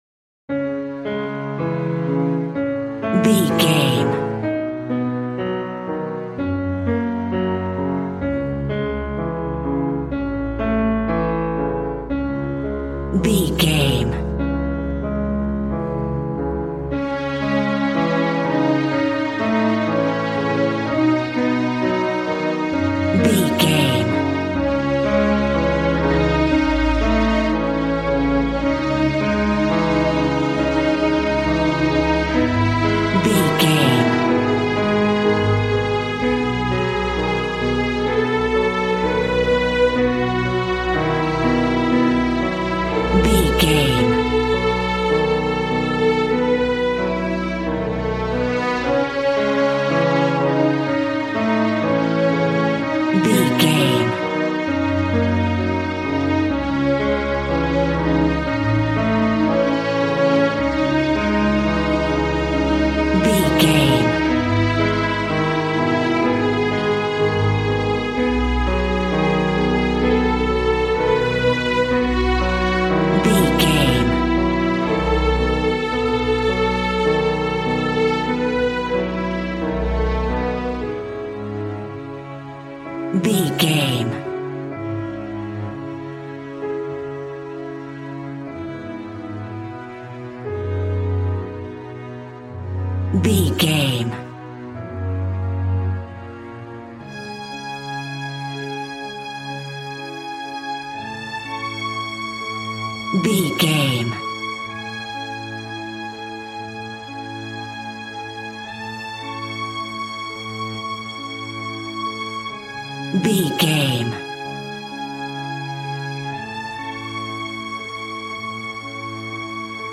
Regal and romantic, a classy piece of classical music.
Aeolian/Minor
D♭
Fast
regal
strings
brass